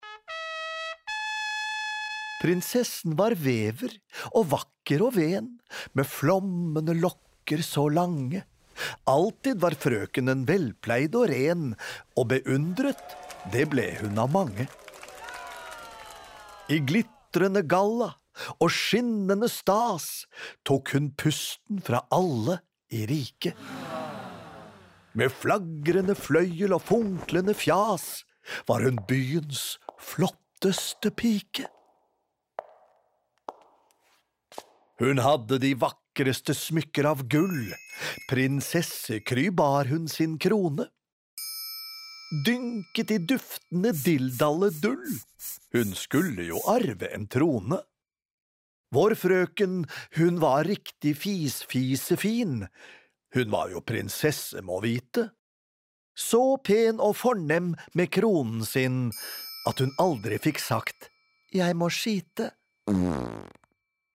Prinsessen på fjerten (lydbok) av Elisabeth Johannessen